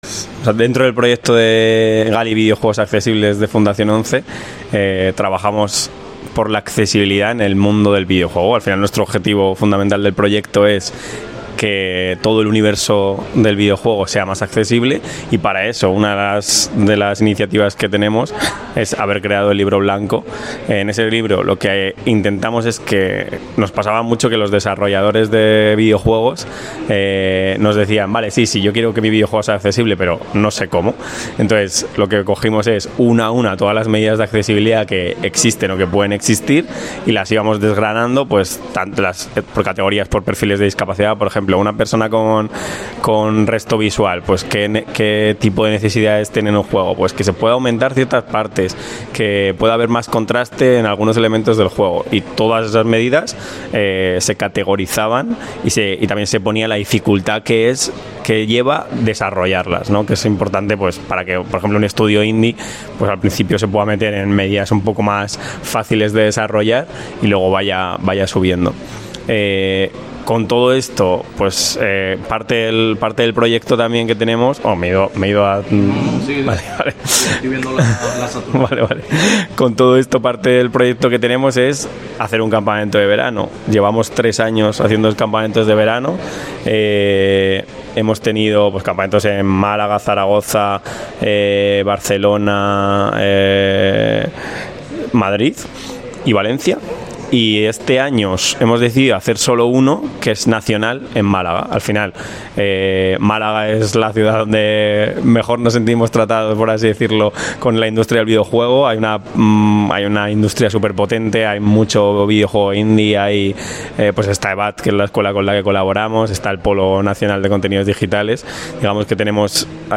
Testiminio